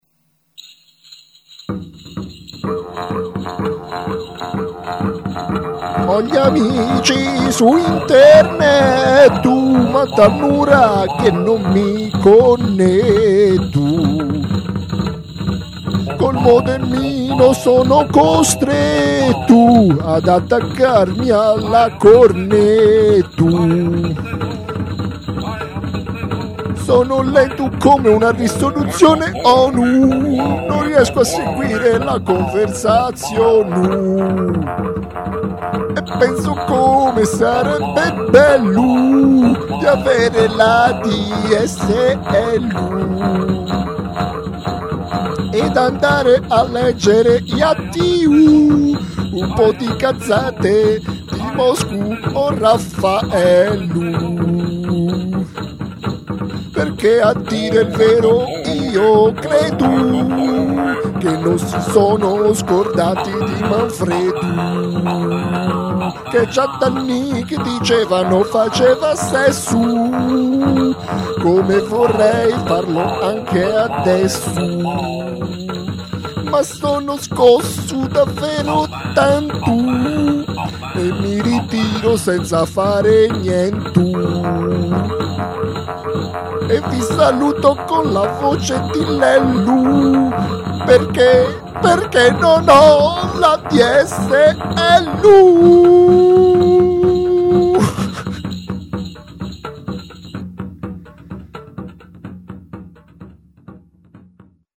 Una canzone tradizionale sarda, forse.